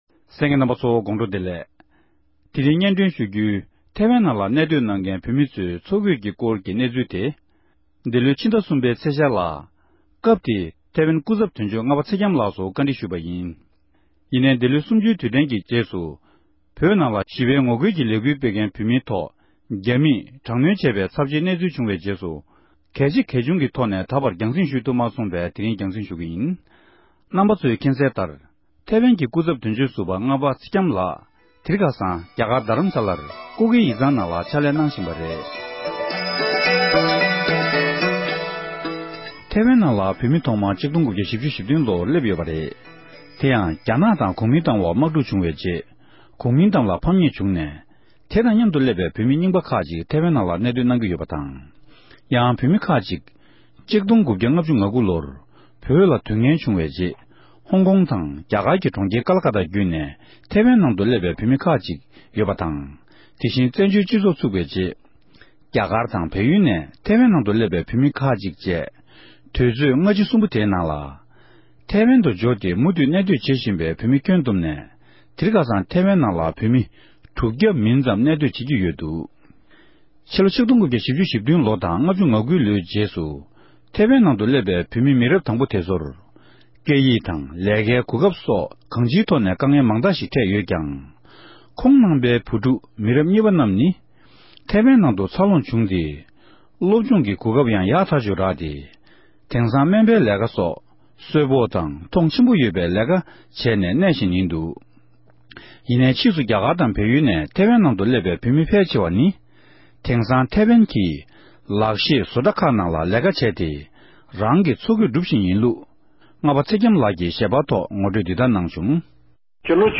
གནས་འདྲི་ཞུས་ཏེ་ཕྱོགས་སྒྲིག་ཞུས་པ་ཞིག་གསན་རོགས་གནང༌༎